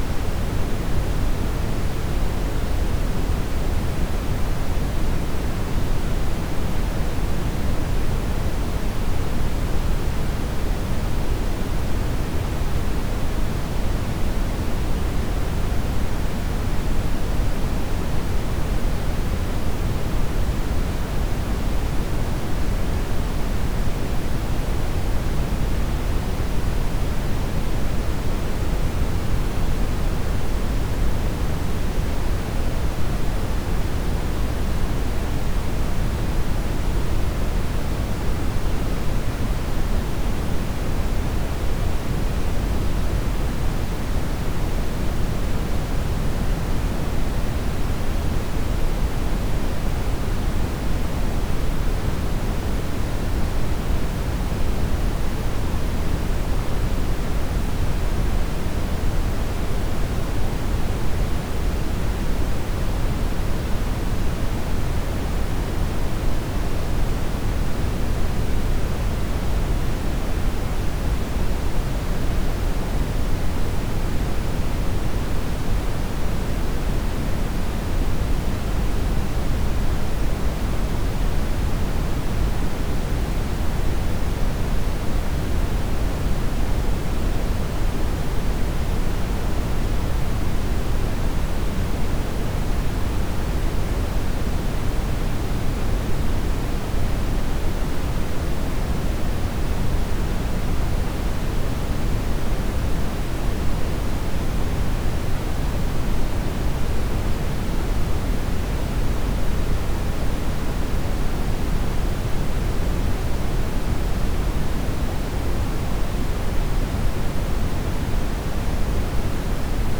(vi) Brownian noise test signals
A recommended type of test signal is broadband Brownian noise, as this has a frequency spectrum that approximates real musical material[1(p. 3),2(p. 87)].
Brownian Noise STEREO -23 LUFS non-coherent L&R 44,1 kHz.wav